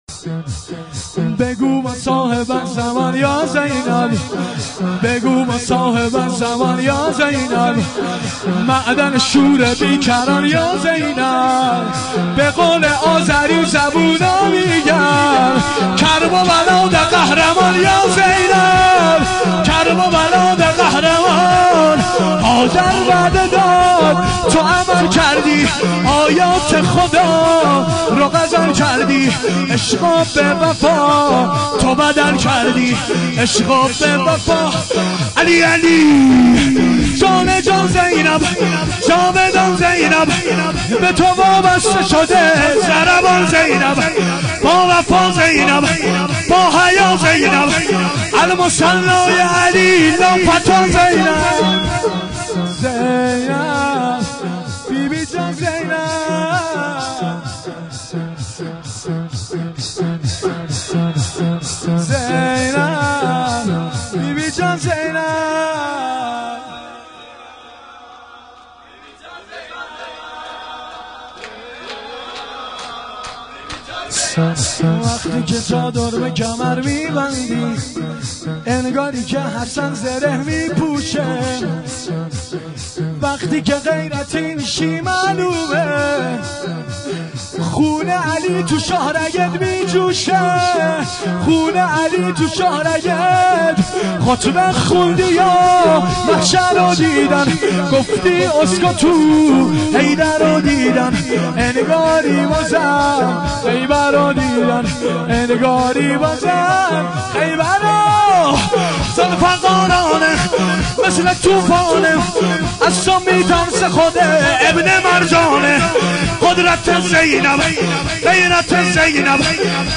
شور
شب اول محرم ۱۴۴۱